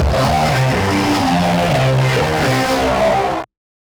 tx_synth_125_flatout_CMin.wav